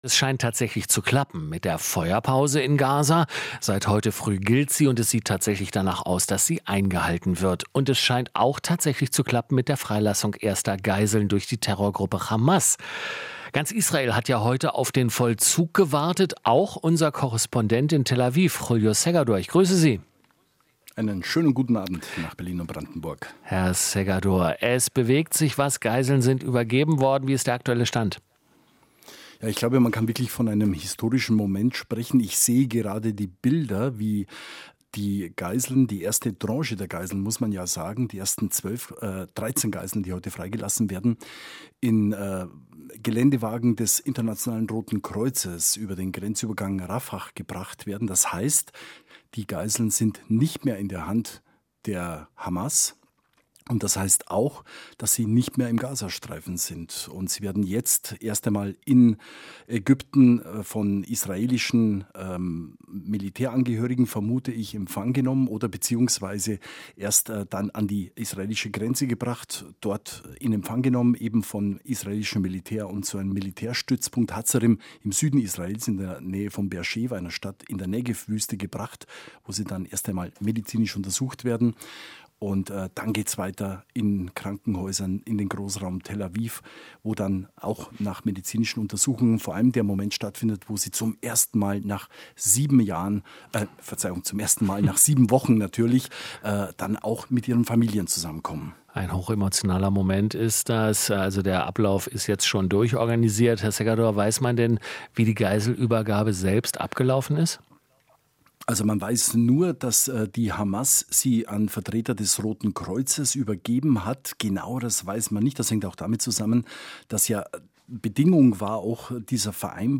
Interview - Feuerpause in Nahost: Erste israelische Geiseln sind frei